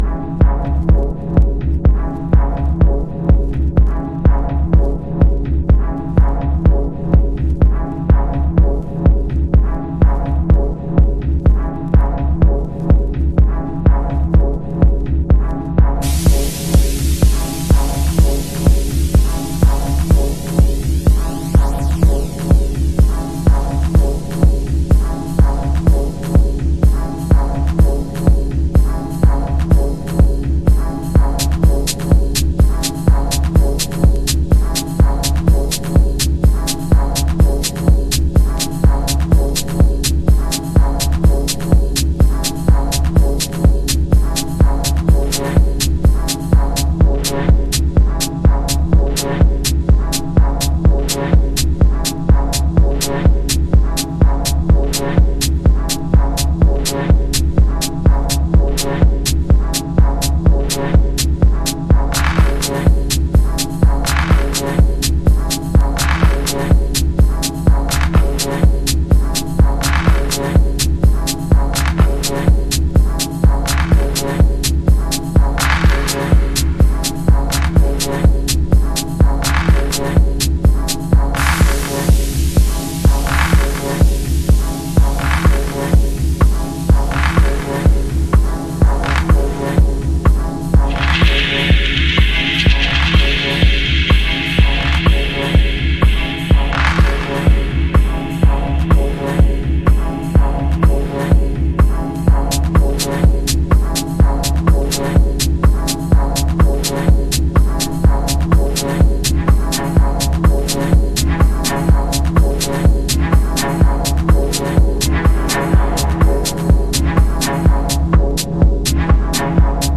House / Techno
疾走する硬質なビートの背後で蠢く不穏なストリングスが印象的なディープテクノ。